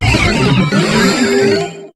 Cri de Pierroteknik dans Pokémon HOME.